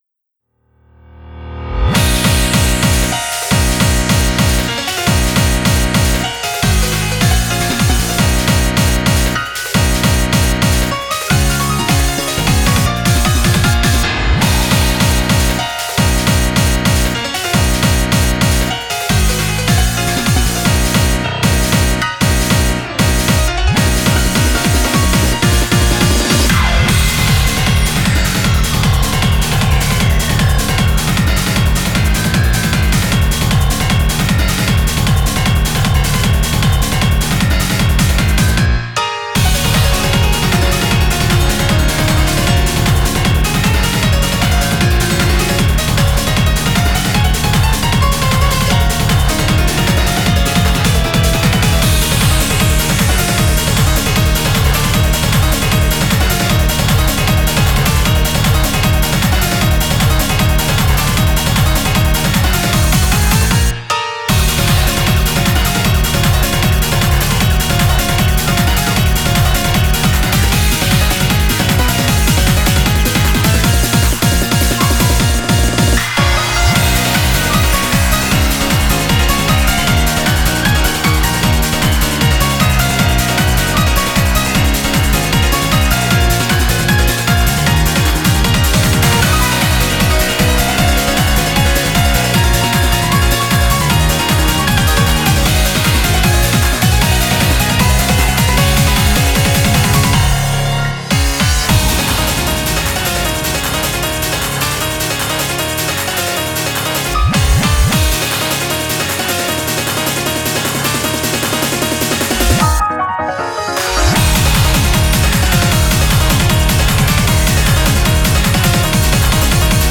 BPM154
Audio QualityMusic Cut
piano